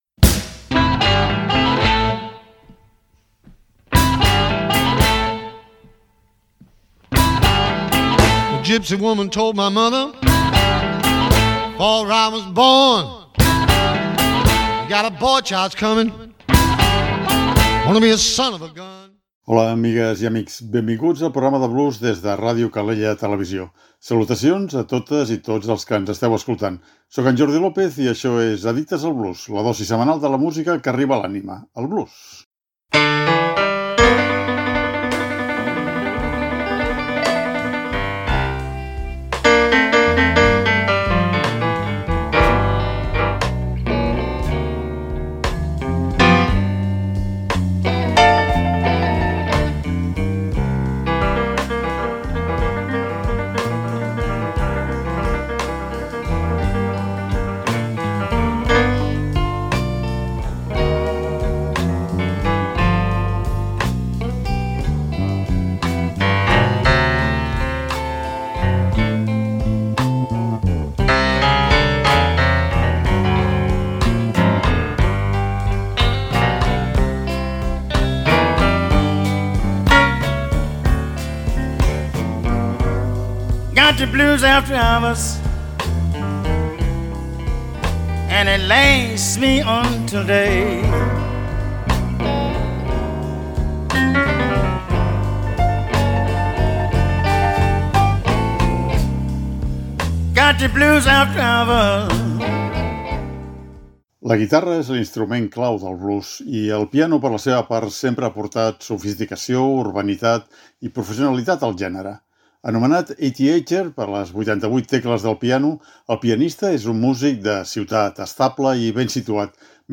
Addictes al Blues: Piano Blues